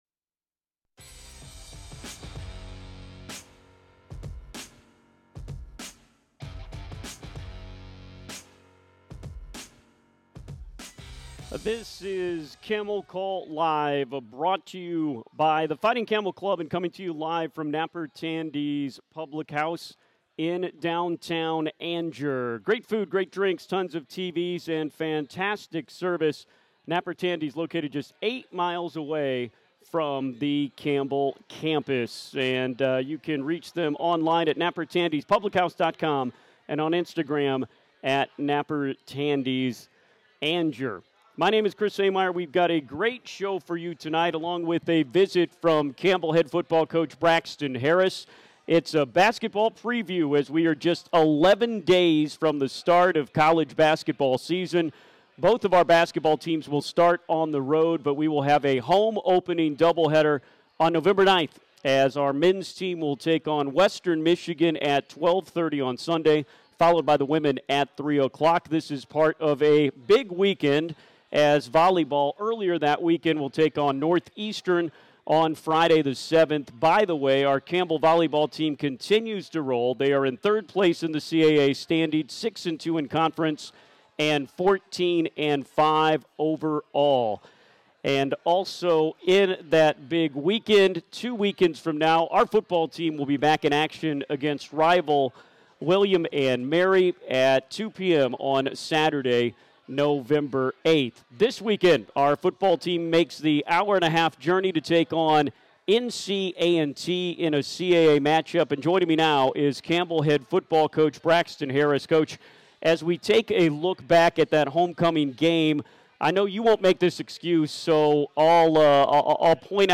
October 24, 2025 It's a basketball preview and football recap as Camel Call Live returns to Napper Tandy's in downtown Angier.